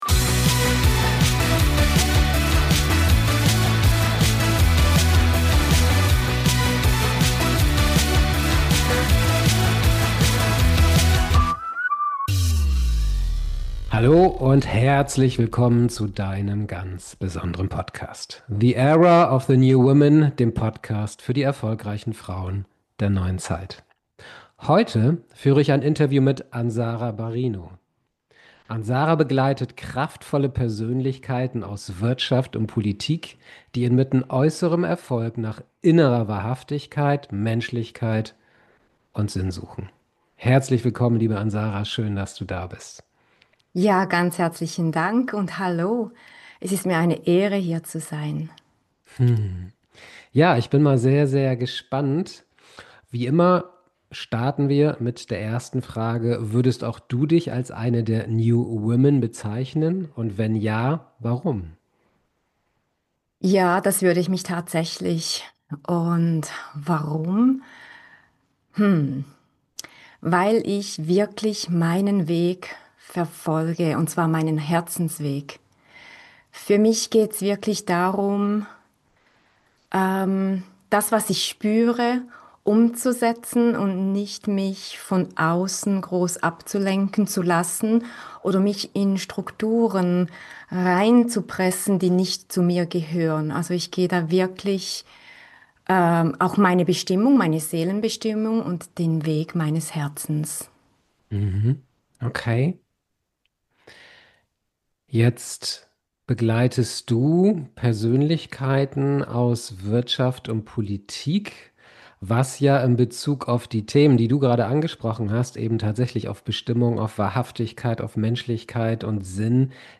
#058 Erfolg ohne Seele bleibt leer. Das Interview